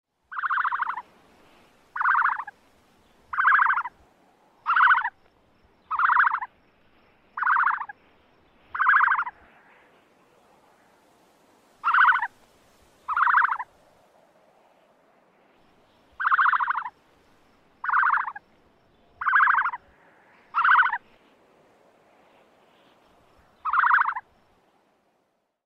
На этой странице вы найдете подборку звуков страуса – от характерного шипения до необычных гортанных криков.
Звук с писком страуса